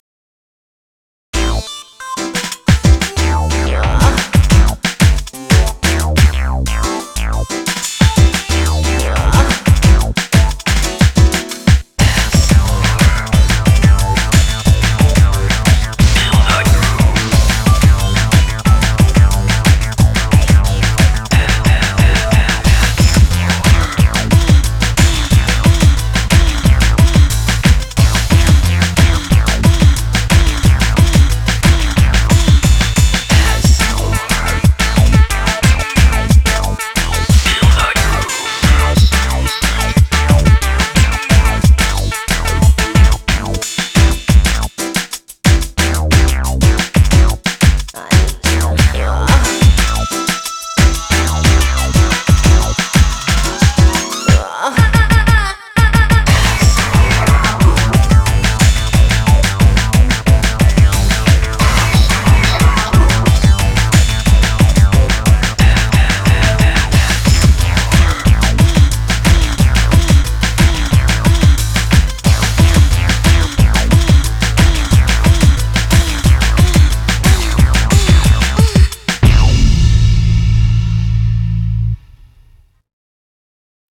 BPM181--1
Audio QualityPerfect (High Quality)